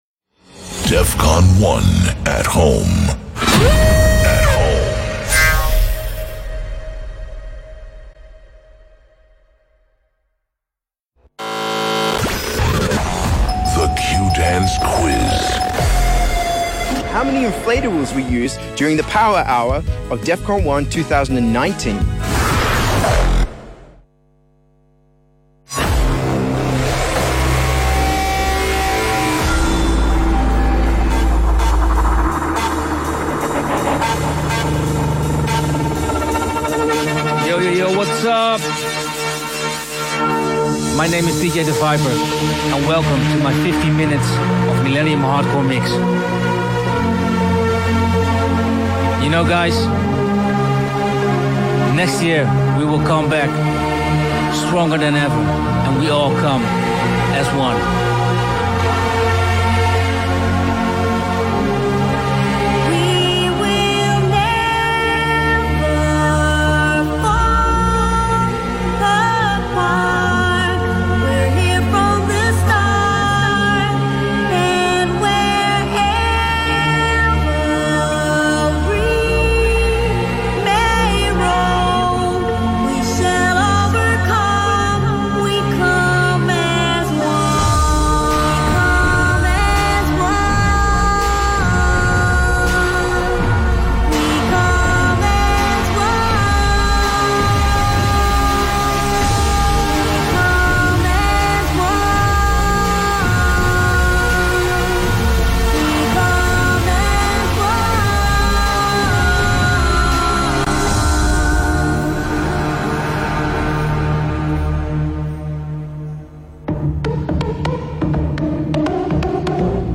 Live Set/DJ mix